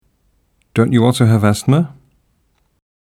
INSTRUCTIONS: Listen to each question and decide whether the speaker is finding out (with a falling tone) or making sure (with a rising tone).